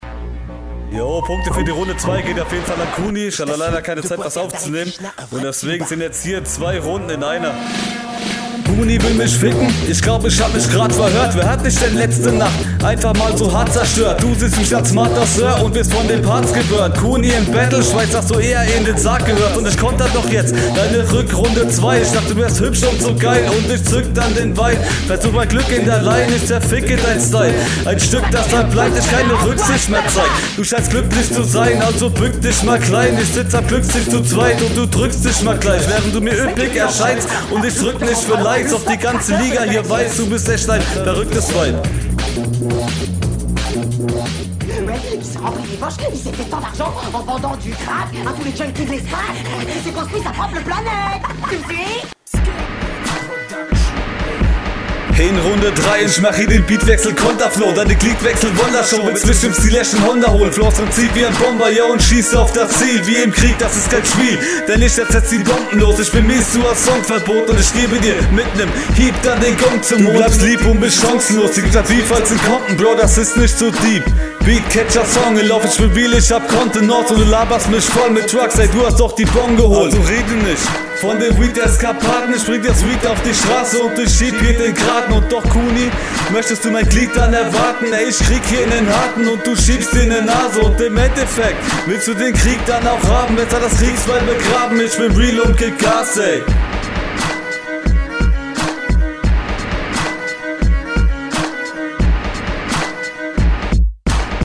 Find dich hier flowtechnisch am schlechtesten, bist hier nicht so präsent wie auf den Runden …
Soundqualität: Geiler Kopfnicker.